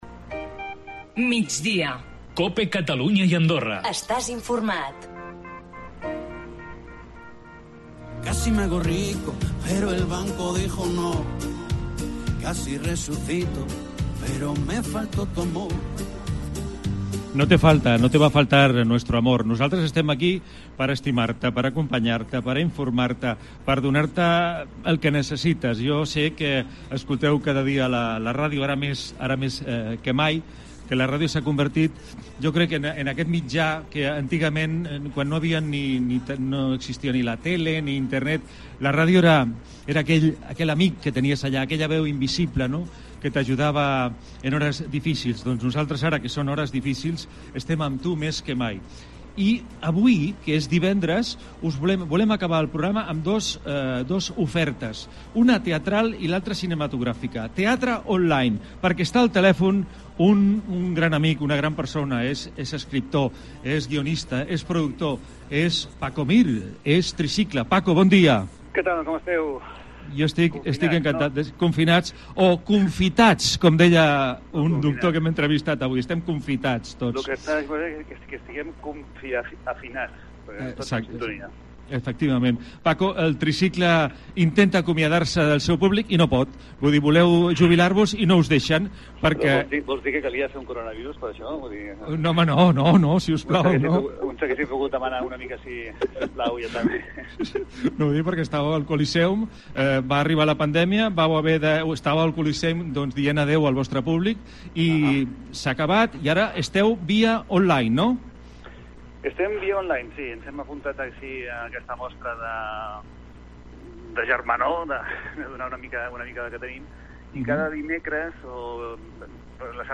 Per Migdia Cope Catalunya i Andorra ha passat l'escriptor, productor, guionista Paco Mir, un dels components del Tricicle.